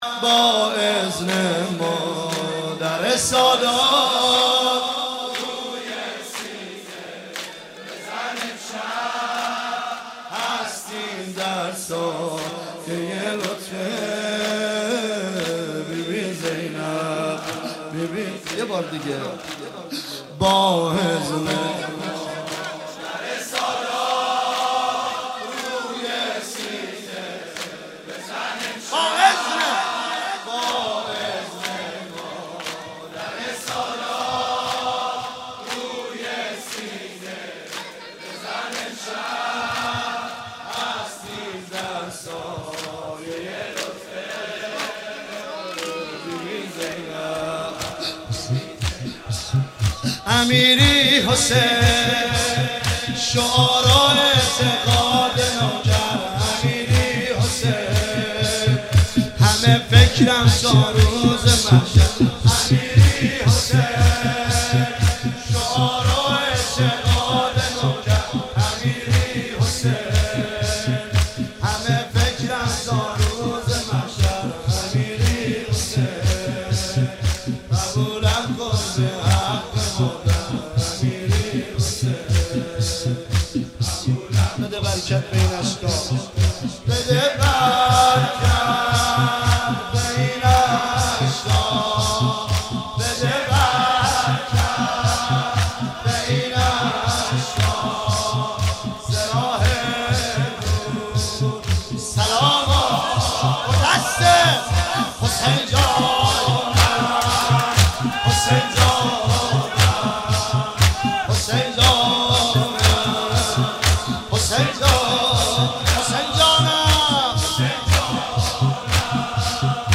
مراسم شب ششم محرم ۱۳۹۷